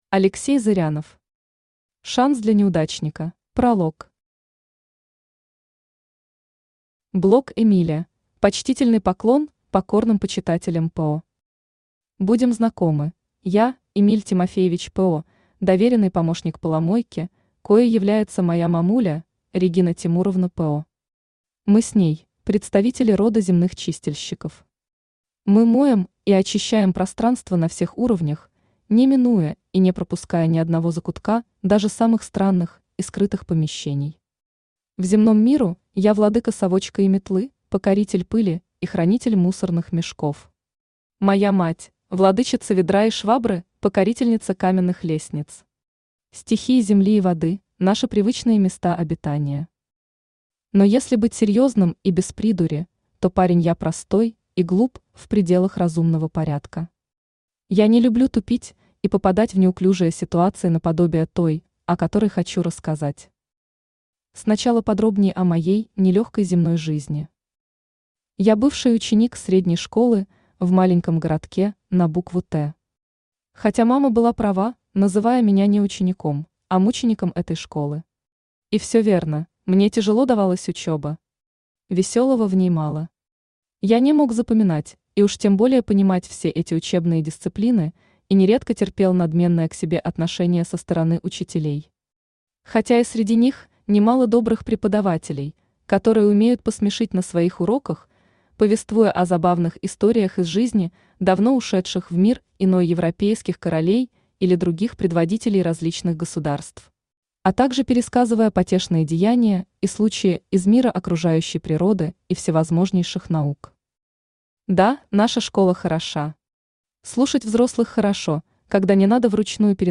Аудиокнига Шанс для неудачника | Библиотека аудиокниг
Aудиокнига Шанс для неудачника Автор Алексей Зырянов Читает аудиокнигу Авточтец ЛитРес.